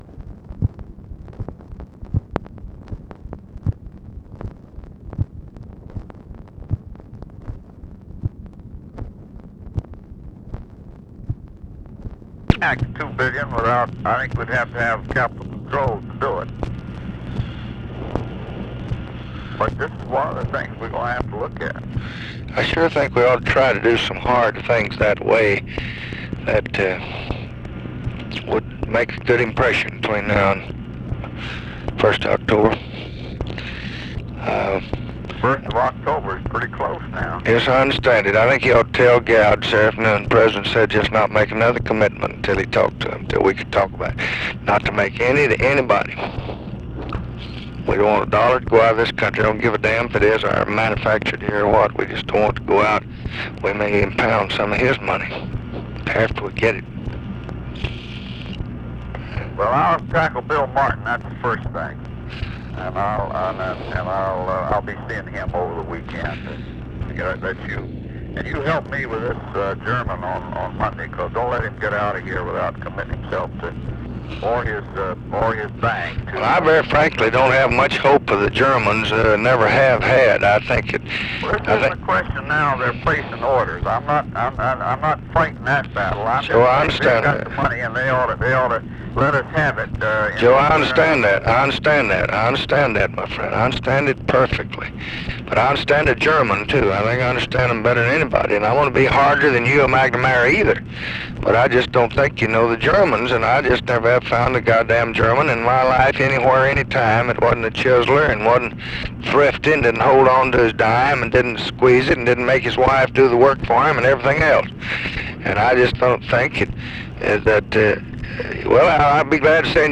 Conversation with HENRY FOWLER, September 24, 1966
Secret White House Tapes